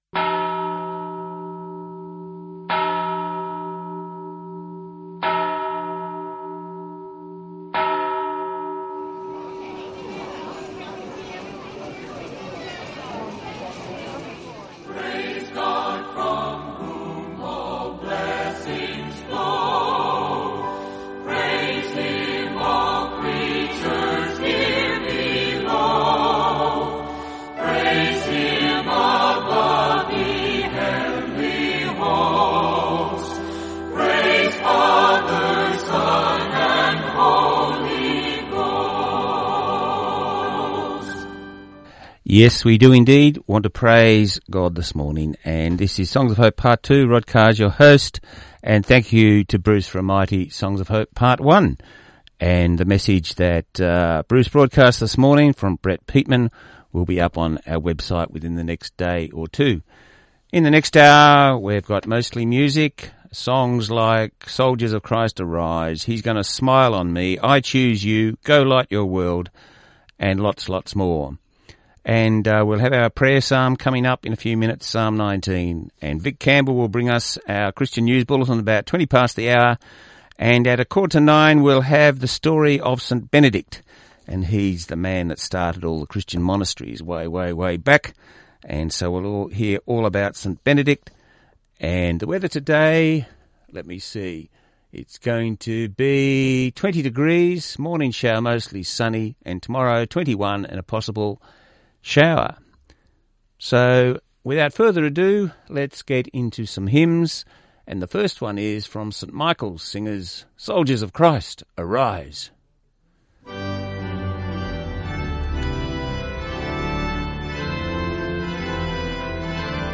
Many Christian songs.